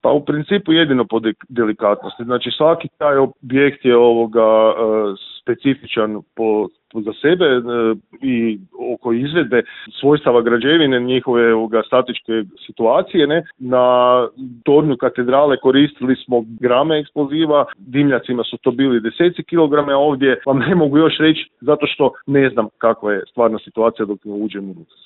razgovarali smo u Intervjuu Media servisa